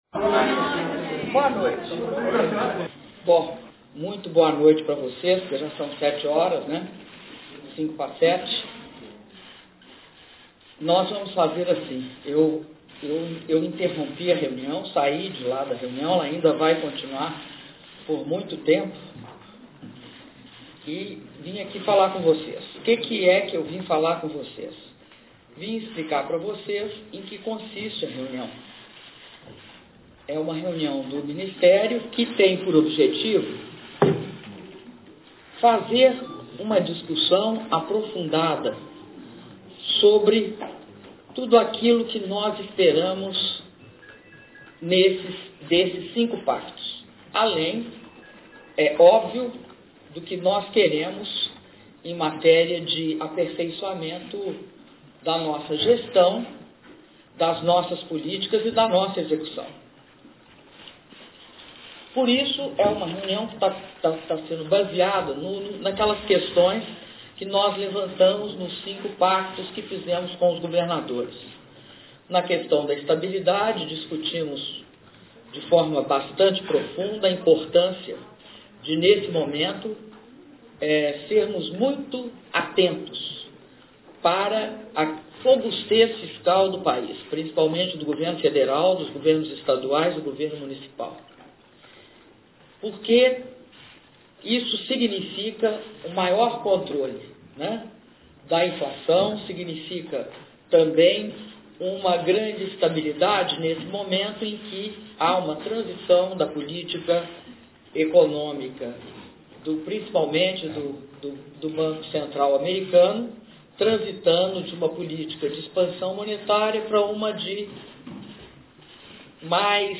Entrevista coletiva concedida pela Presidenta da República, Dilma Rousseff, durante reunião ministerial